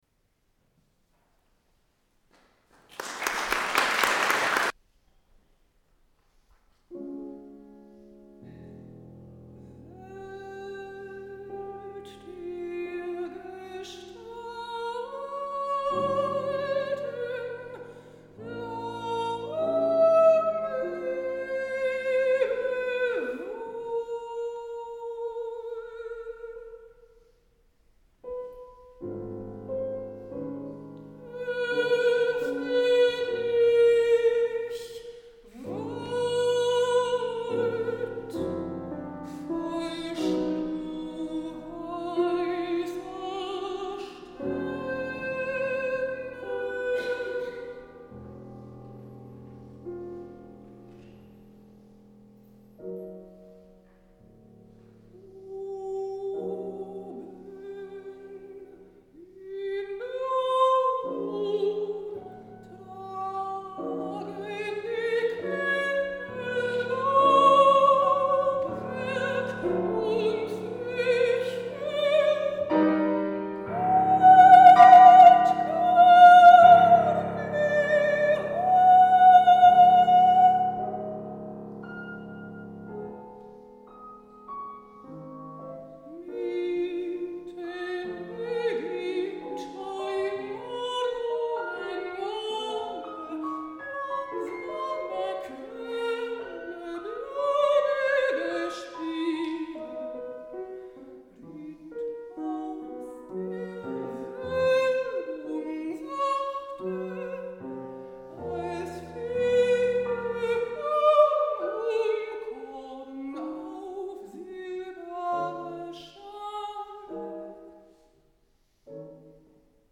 Mitschnitt eines Liederabends
Juli 1994 im Sorbischen Museum Bautzen
Mezzosopran
Klavier